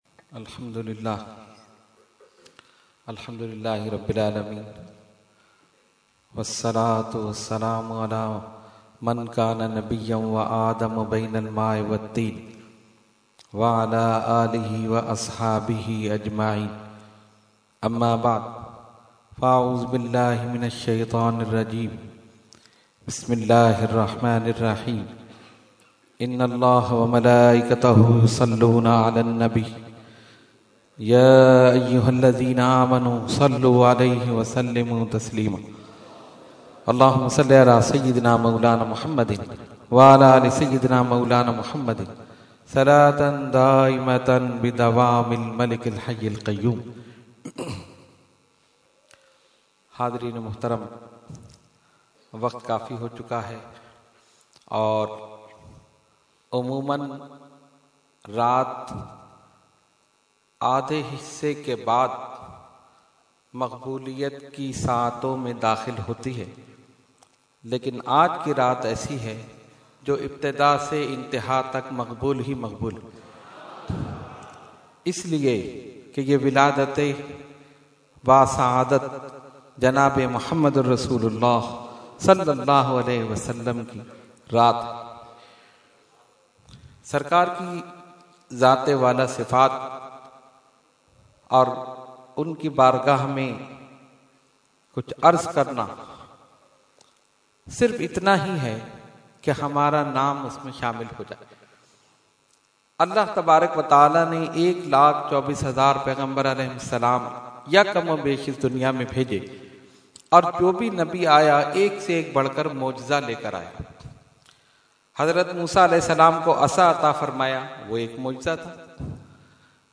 Category : Speech | Language : UrduEvent : Jashne Subah Baharan 2015